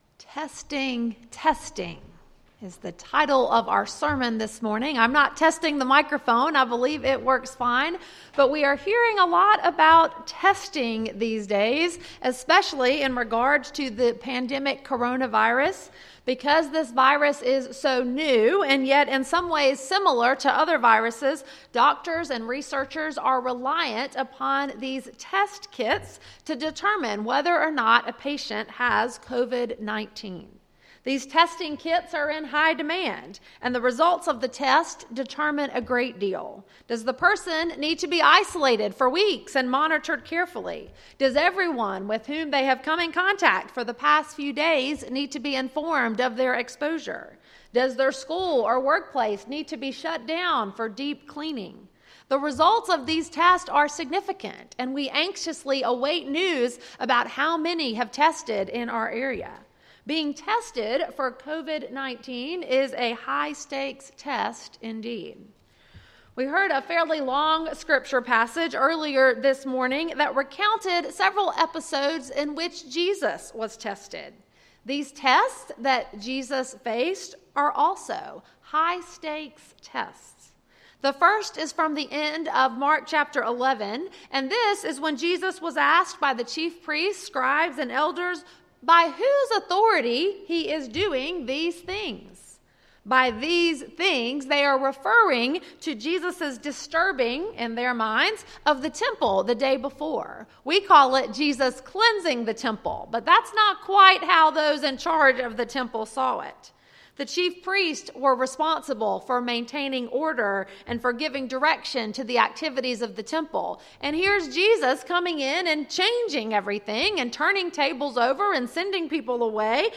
Sermon, Worship Guide, and Announcements for March 15, 2020 - First Baptist Church of Pendleton